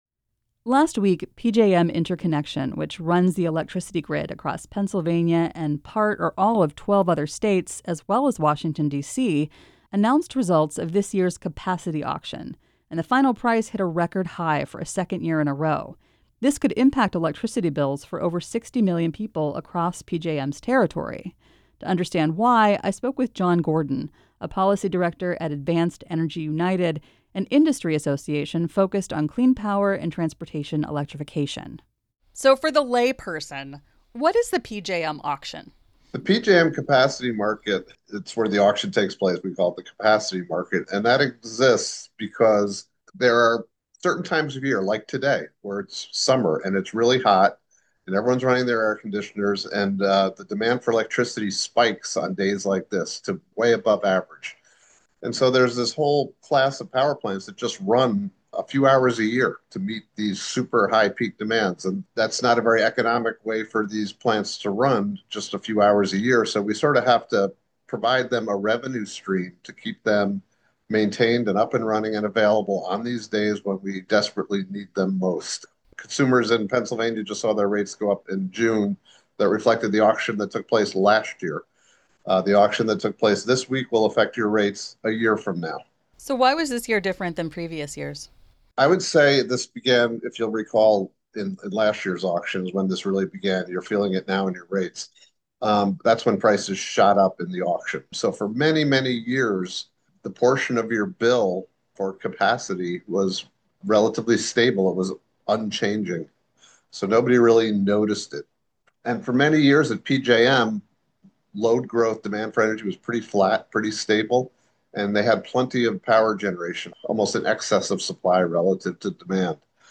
The interview has been edited.